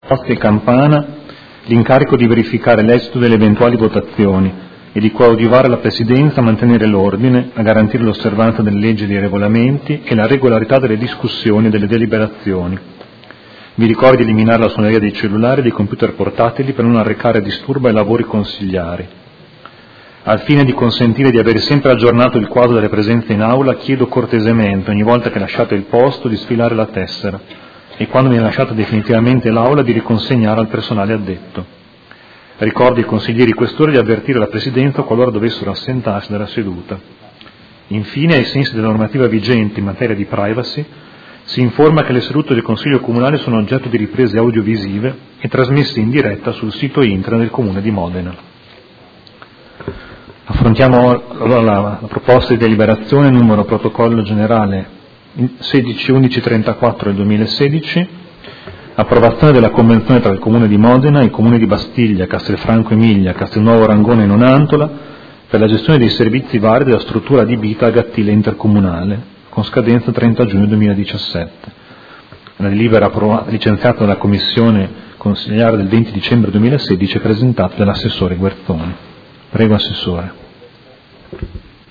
Seduta del 19/01/2017 Apertura del Consiglio Comunale